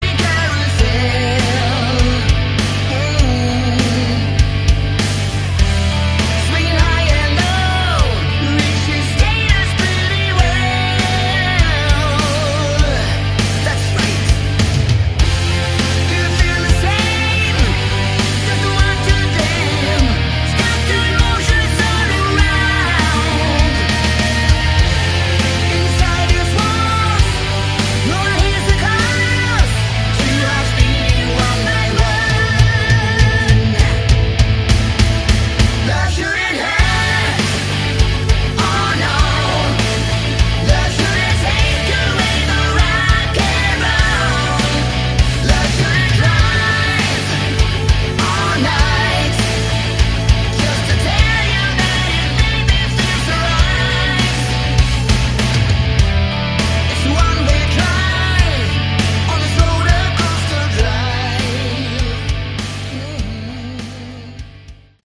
Category: Melodic Hard Rock
lead vocals
lead guitar
guitar
bass
drums